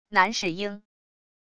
男侍应wav音频
男侍应wav音频生成系统WAV Audio Player